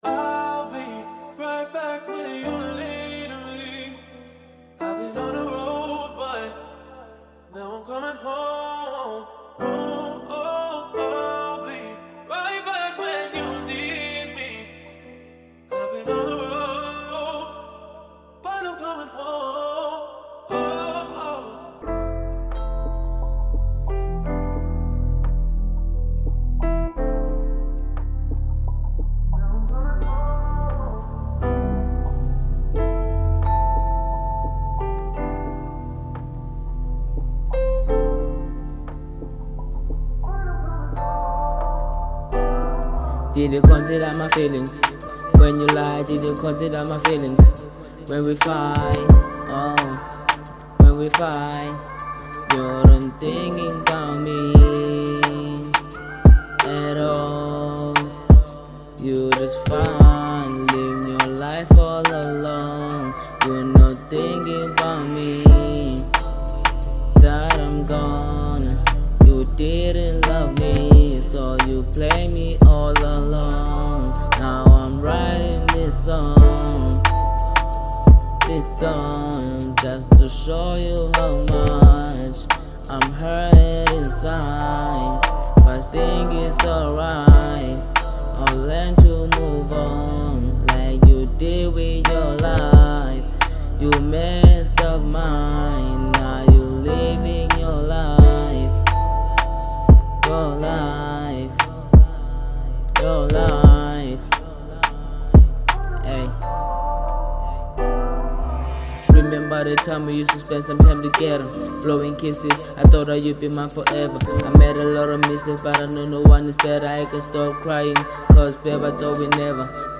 03:05 Genre : RnB Size